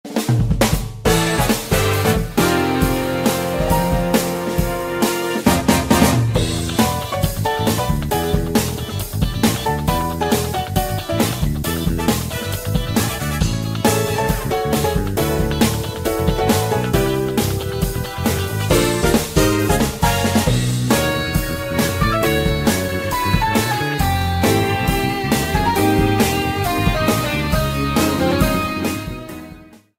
This is a sample from a copyrighted musical recording.
applied fadeout